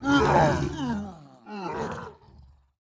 Index of /App/sound/monster/orc_general
dead_1.wav